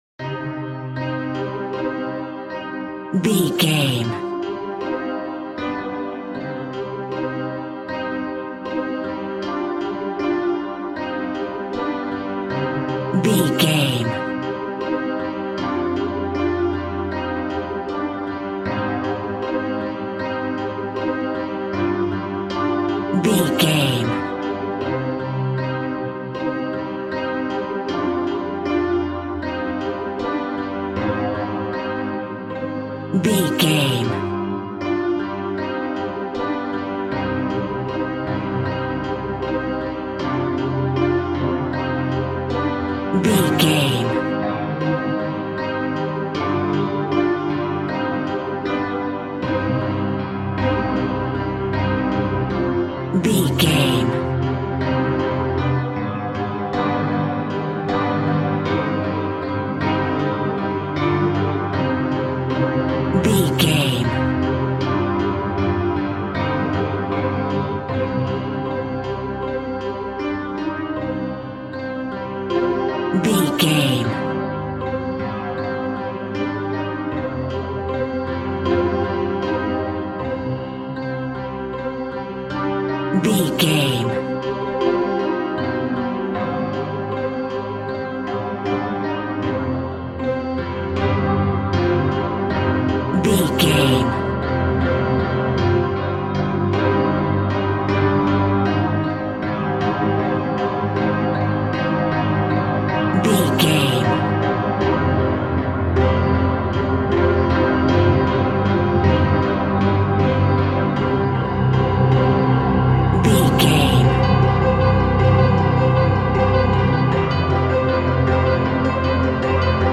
Twisted Piano Horror Music.
Aeolian/Minor
ominous
dark
haunting
eerie
sythesizer
Acoustic Piano